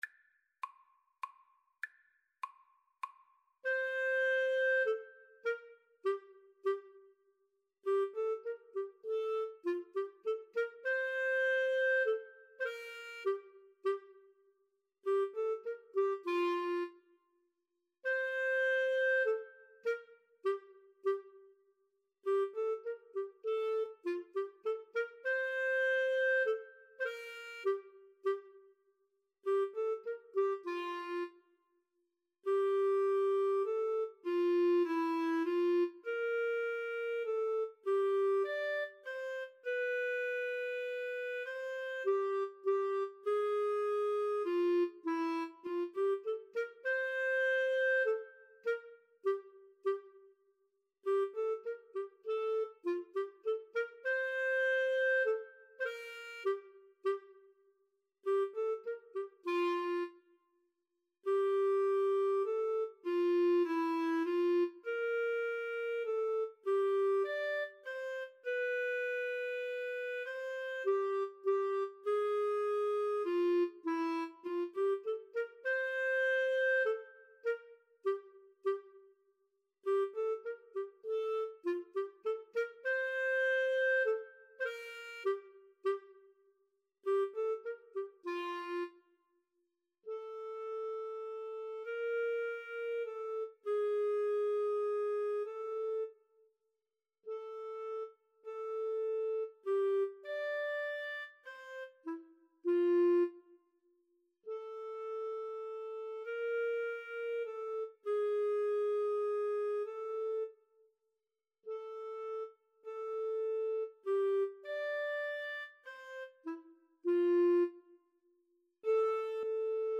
Allegretto - Menuetto
Classical (View more Classical Clarinet-Viola Duet Music)